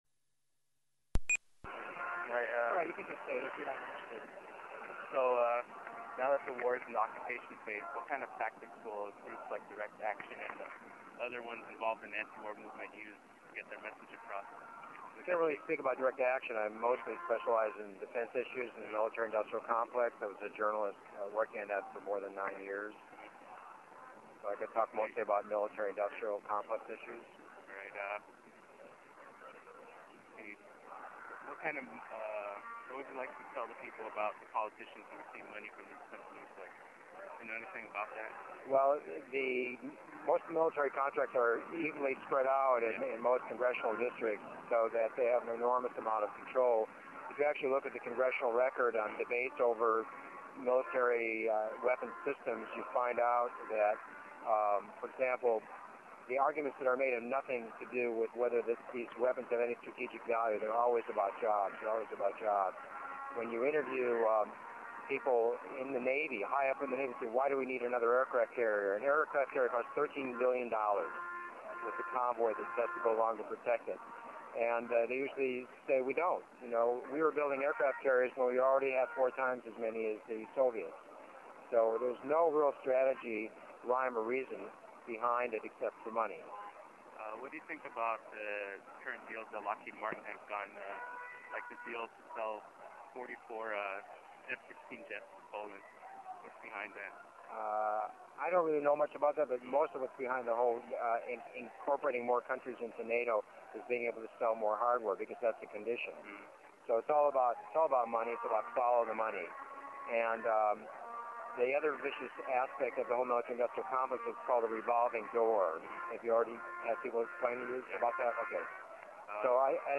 Sunnyvale CA, April 23rd
§missing interview 4
this is the missing interview 4, posted interview 3 twice, my bad.